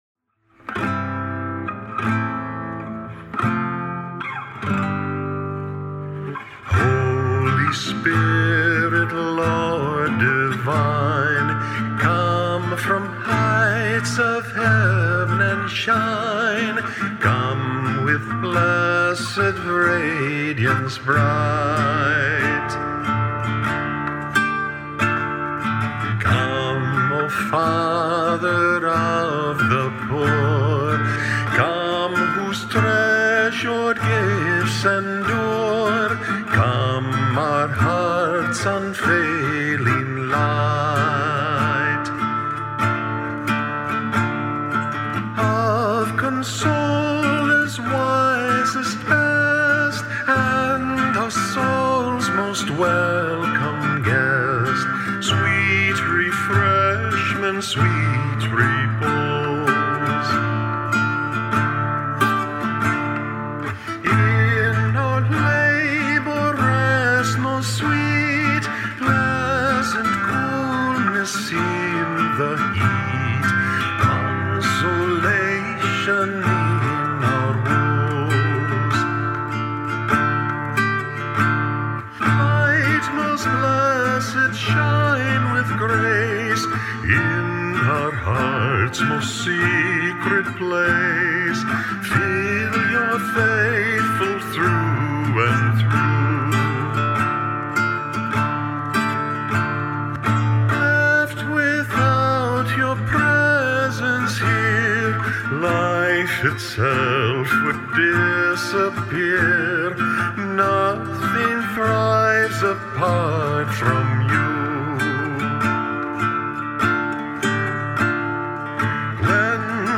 Sequence for Pentecost: Mode I (guitar and voice) | Top Catholic Songs
Voice and 6-string nylon string guitar
Tune: Mode I; accompaniment by Adrian Engels, © Interkerkelijke Stichting voor het Kerklied. There are two days on the church calendar (Easter and Pentecost) when the liturgy calls for a "sequence," a special hymn that may be sung or recited before the gospel.